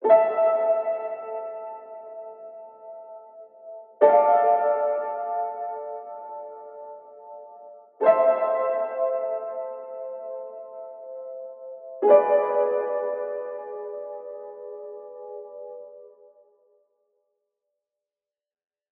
Distant_01_F#min_60BPM
Distant_01_Fmin_60BPM.wav